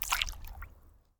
water-splash-07
bath bathroom bubble burp click drain dribble dripping sound effect free sound royalty free Nature